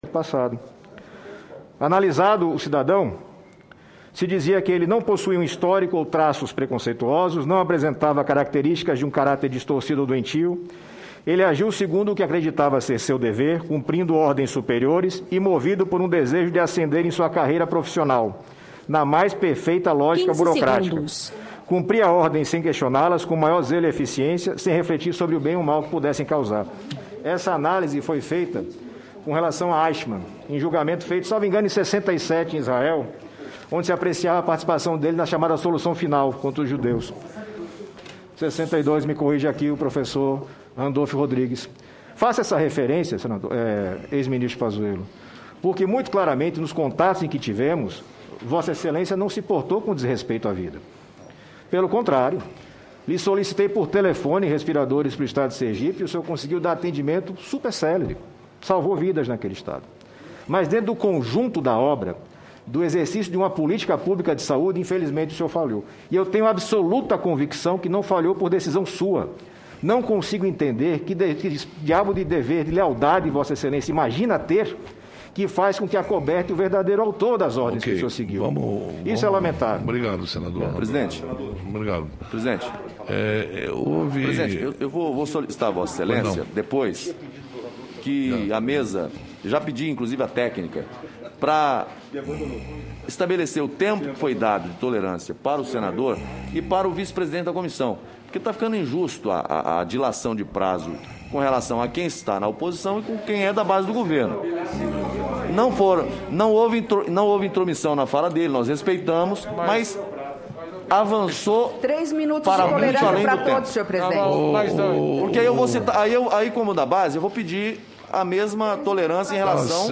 Por fim, chamou a atenção que foi usado um dispositivo regimental nessa sessão para excluir da transcrição a menção ao nazista Eichmann feita pelo senador Alessandro Vieira, quando comparou o julgamento pela participação no extermínio de judeus na Segunda Guerra Mundial com a condução da pandemia por Pazuello.